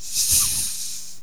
snake_attack5.wav